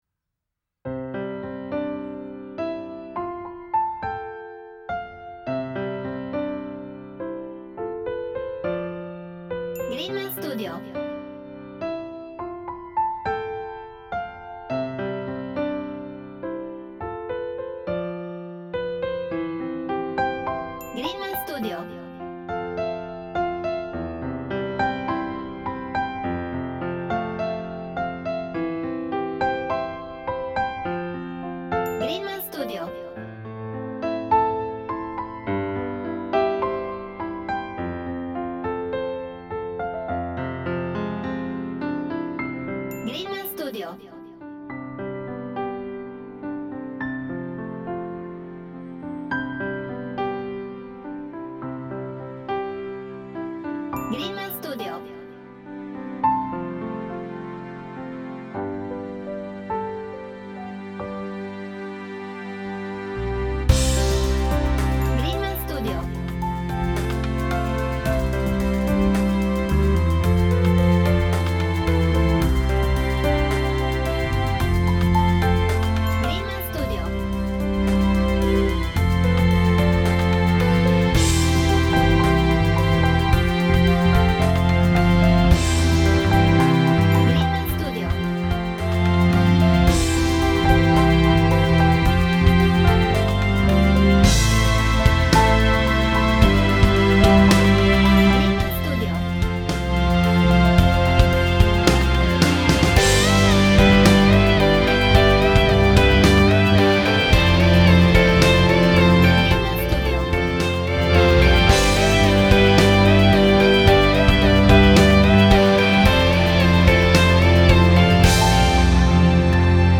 Relaxed/Romantic